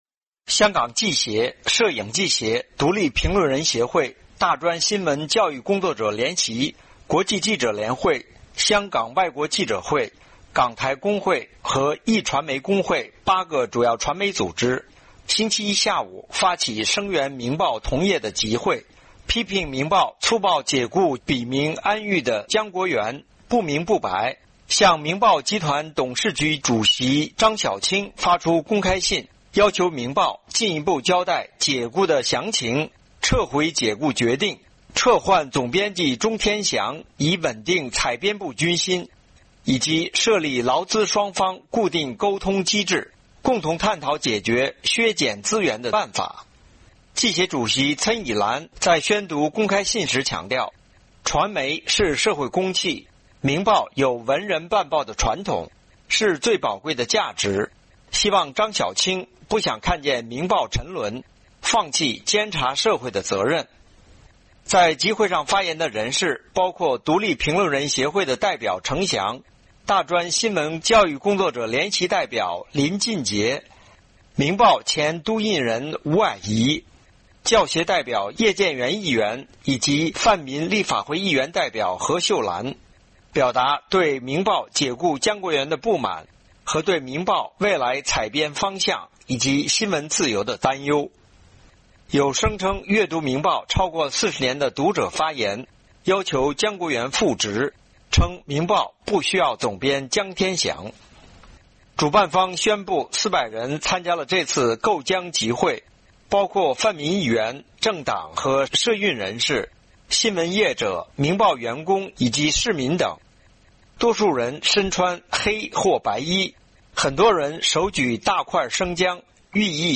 香港新闻人在明报报社外集会守护新闻自由